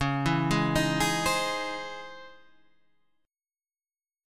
C# Minor Major 9th